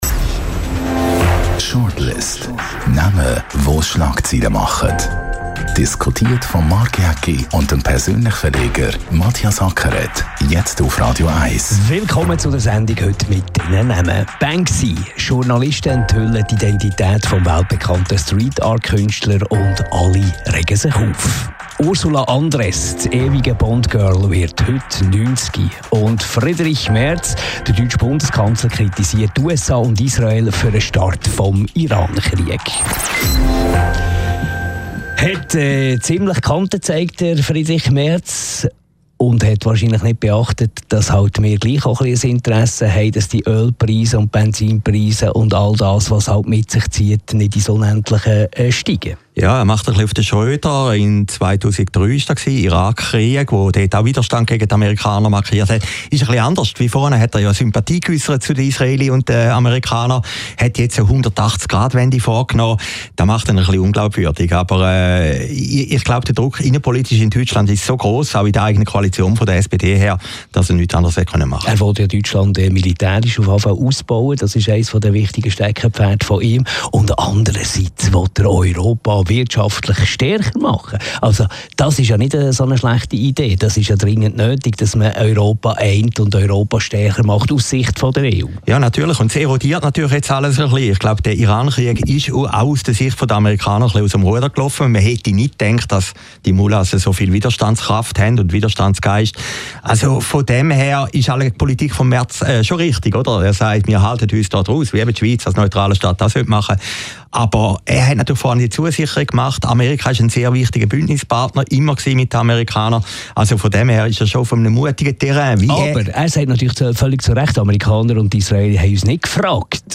Die Talkshow
Jeden Donnerstag nach 18 Uhr diskutieren die beiden Journalisten über Persönlichkeiten, die für Schlagzeilen sorgen.